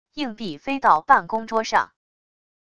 硬币飞到办公桌上wav音频